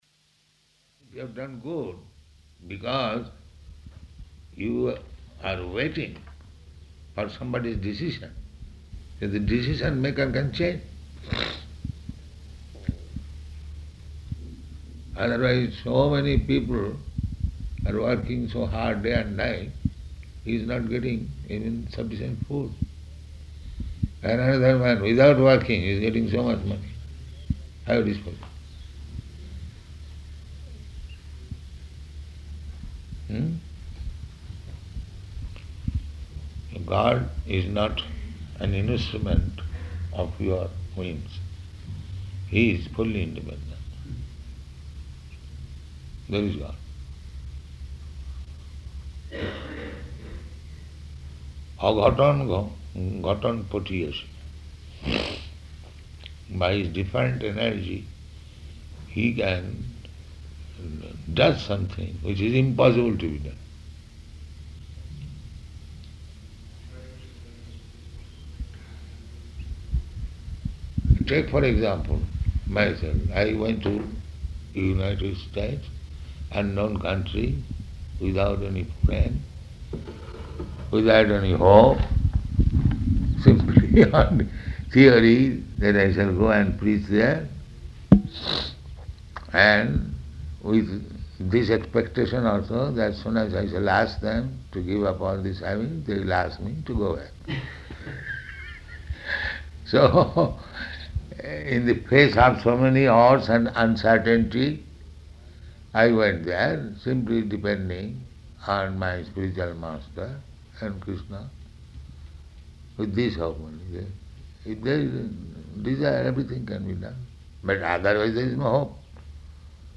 Room Conversation